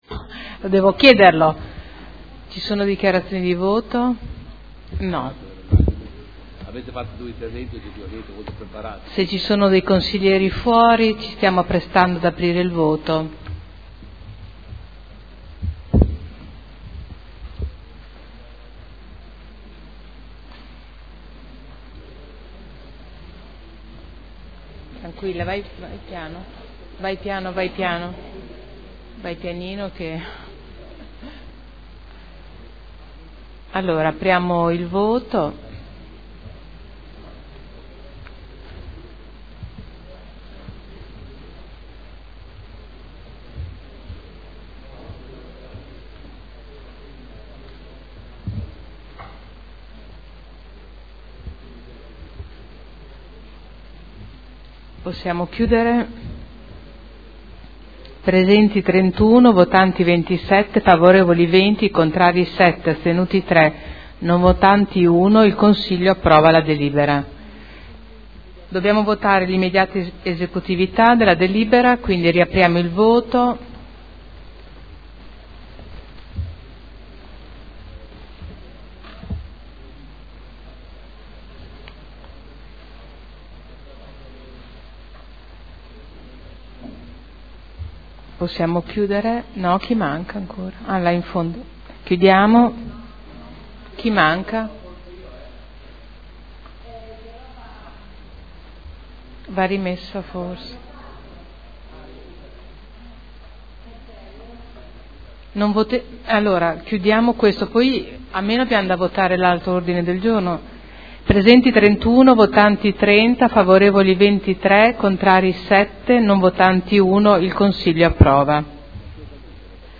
Presidente — Sito Audio Consiglio Comunale
Seduta del 24 marzo. Proposta di deliberazione: Fusione per incorporazione di AMGA Azienda Multiservizi di Udine in Hera e modifiche allo Statuto Hera.